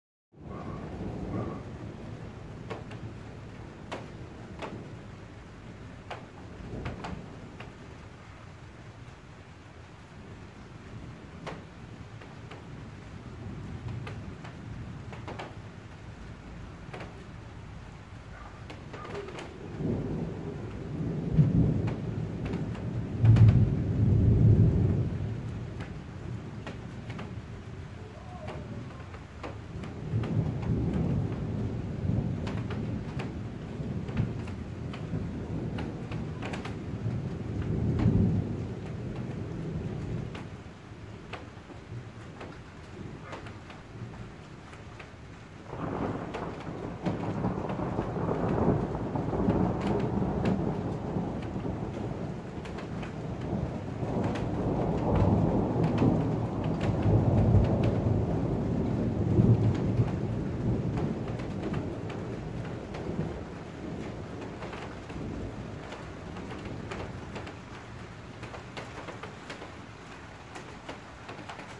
雷电+雨水2
描述：遥远的雷声+雨
Tag: trueno 暴风 暴雨 气候 雷电 暴雨 雷暴